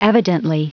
Prononciation du mot evidently en anglais (fichier audio)
Prononciation du mot : evidently